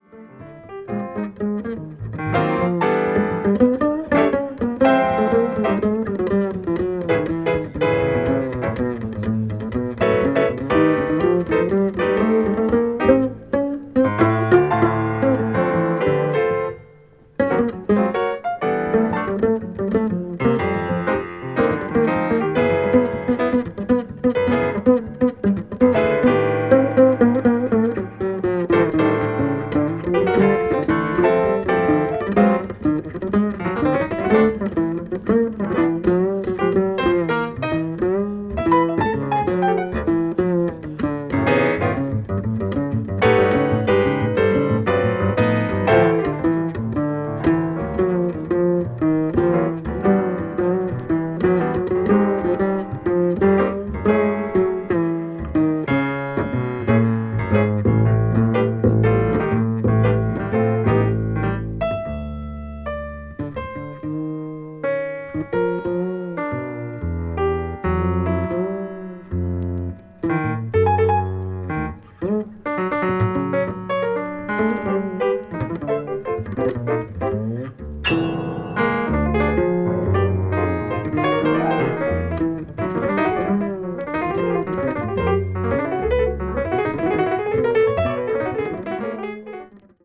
アヴァンなインタープレイでありながらも情緒あふれるパフォーマンスが素晴らしいデュエット。